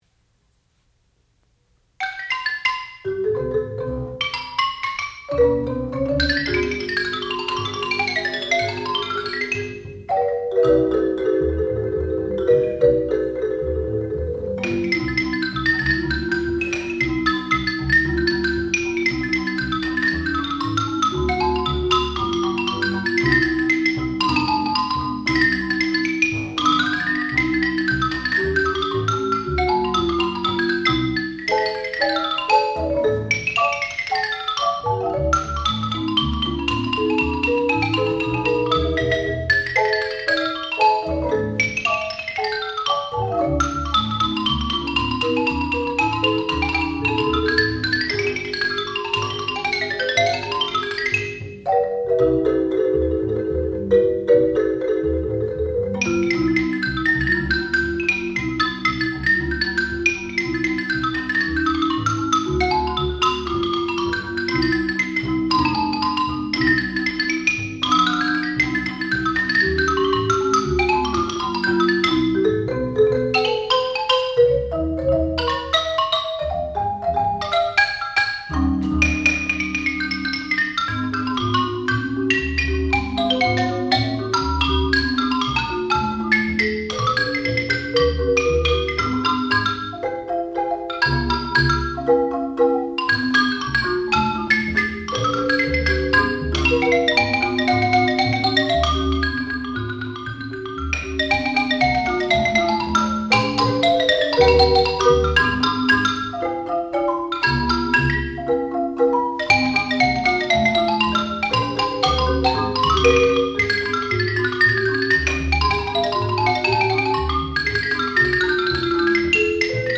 classical music
xylophone
marimba
Master's Recital
percussion